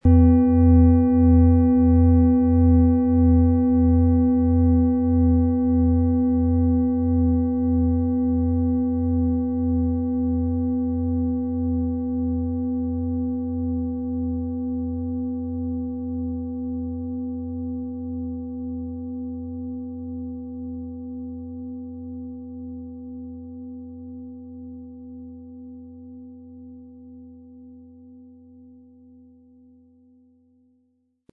Planetenton
Diese tibetische Klangschale mit dem Ton von Sonne wurde von Hand gearbeitet.
Im Sound-Player - Jetzt reinhören hören Sie den Original-Ton dieser Schale.
SchalenformBihar
MaterialBronze